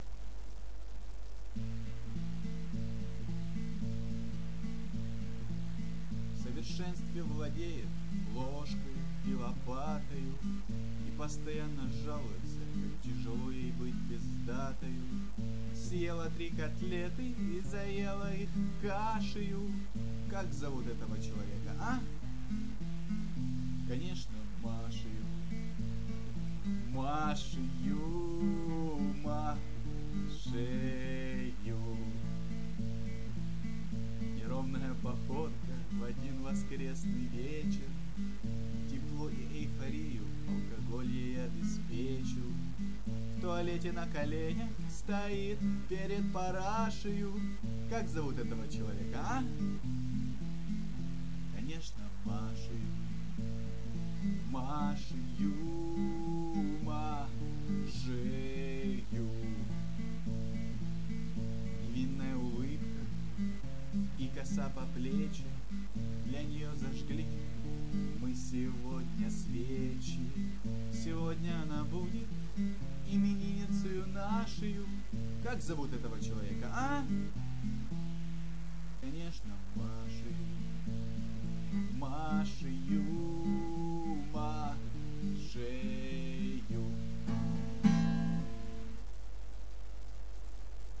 Я пою)
Простите за плохое качествоfrown